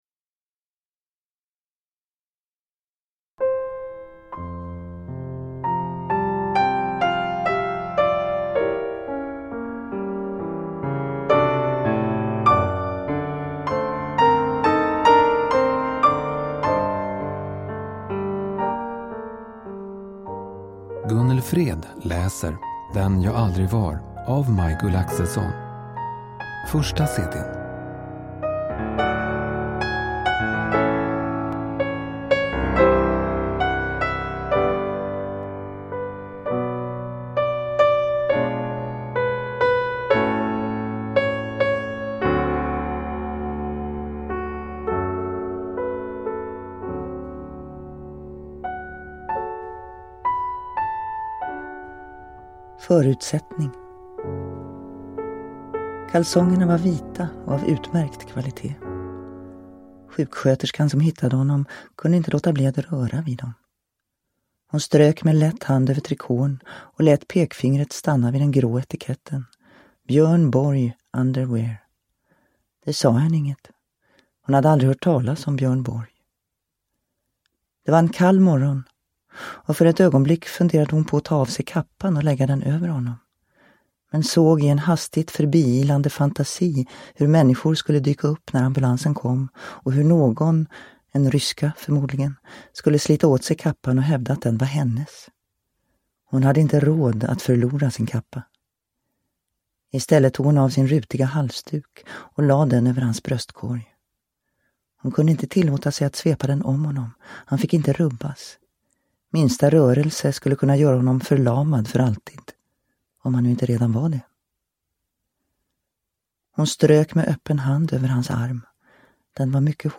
Den jag aldrig var – Ljudbok – Laddas ner